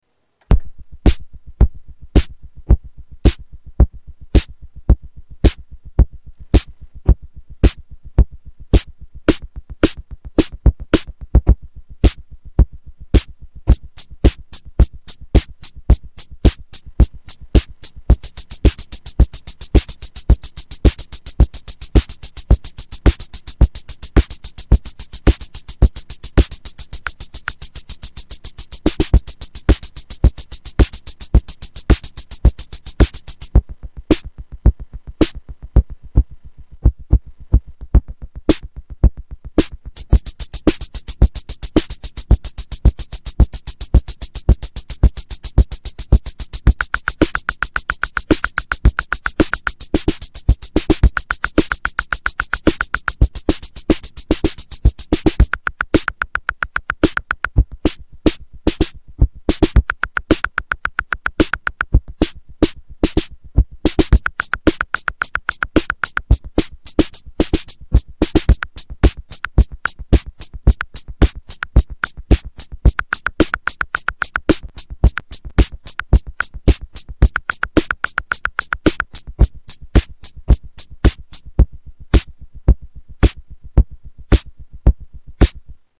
Tags: Boss Drum machine DR-55 Dr. Rhythm Drum machine sounds DR-55